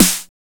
909 ACID SN.wav